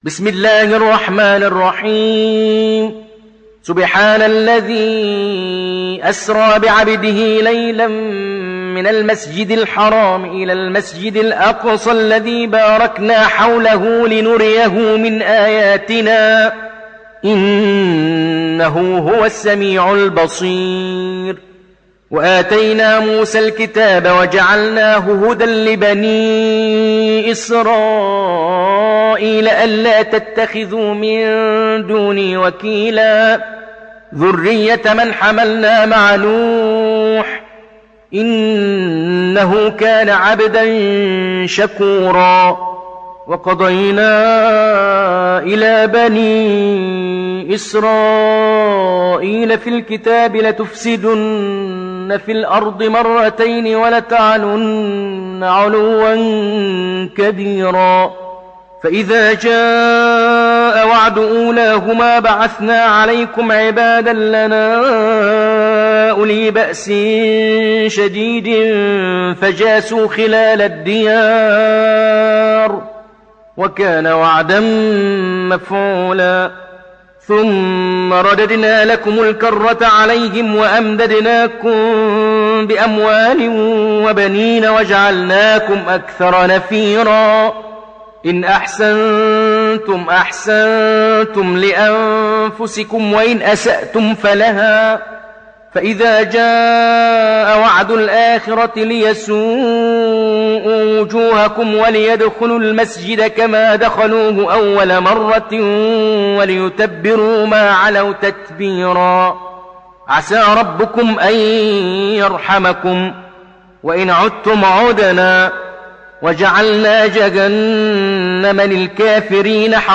(Riwayat Hafs)